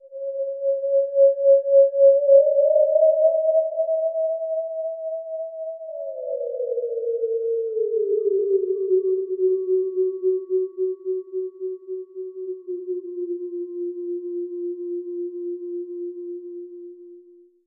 ho_ghost_theremin_03_hpx
Ghostly howling sounds resonate with reverb.